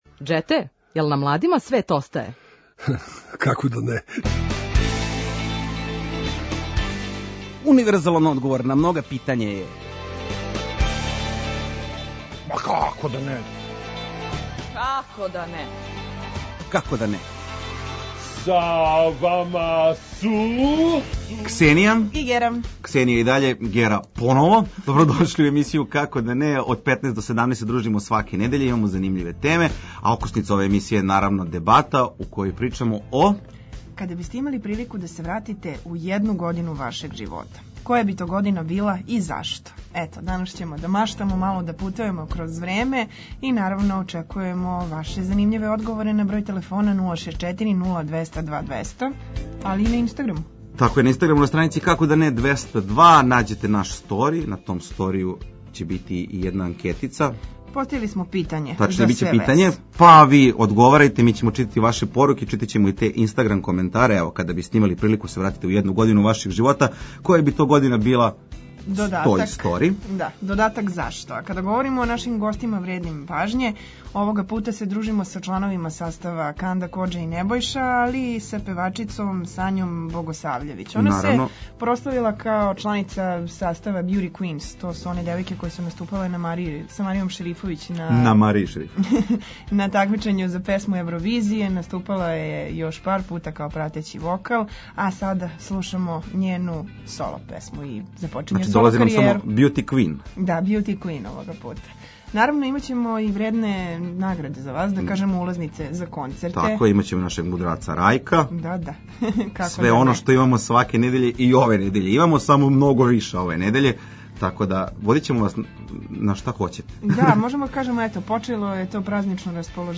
Okosnica emisije je „Debata” u kojoj ćemo razmeniti mišljenja o različitim temama i dilemama. Kada biste imali priliku da se vratite u jednu godinu svog života, koja bi to godina bila i zašto?